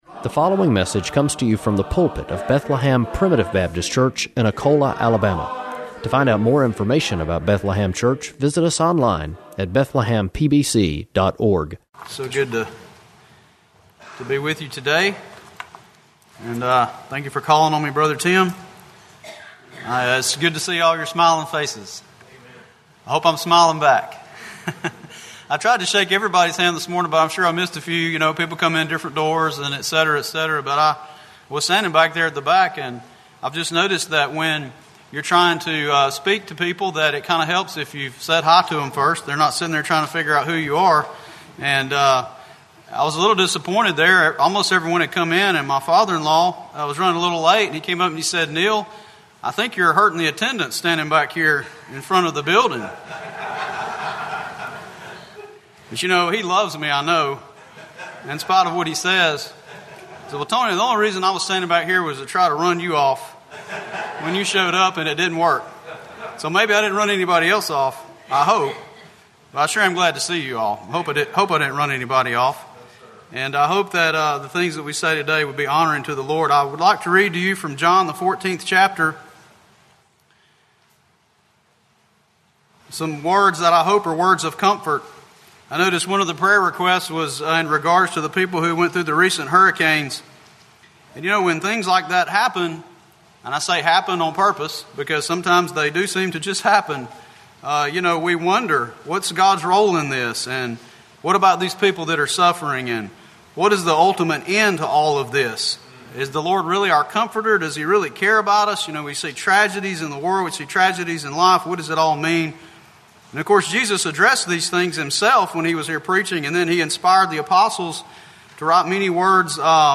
– Bethlehem Primitive Baptist Church